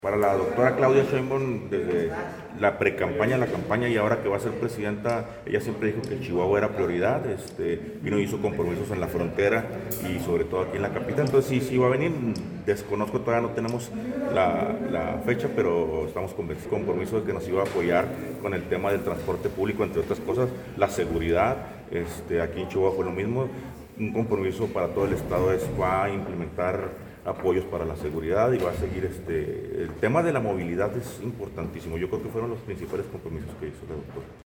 AUDIO: HUGO GONZALEZ, REGIDOR DE FRACCIÓN EDILICA DE MOVIMIENTO REGENERACIÓN NACIONAL (MORENA)